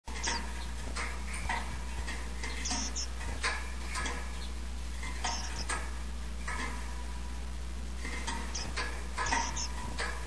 さえずりのコーナー（メジロ編）
ジィジィ 50KB ケージを暴れまわって、ジィジィと細い声で鳴いています。
saezuri-jiji.mp3